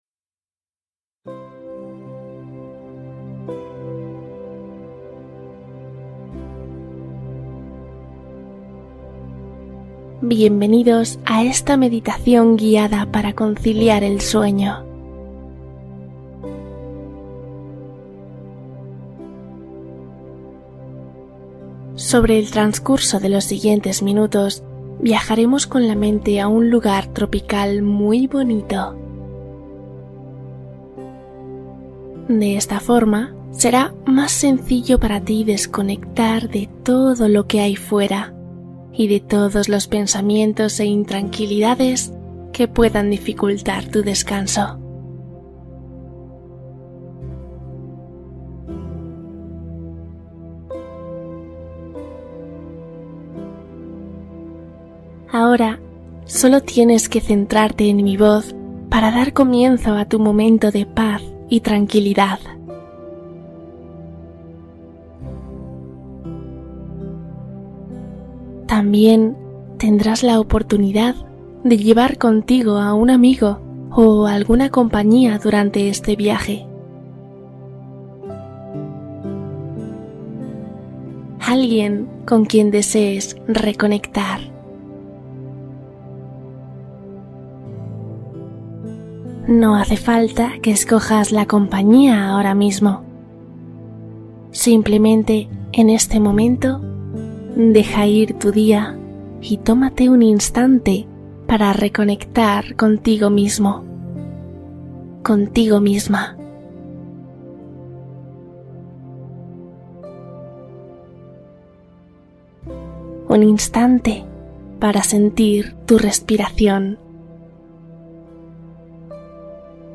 Meditación suprema para dormir con calma y sonidos del mar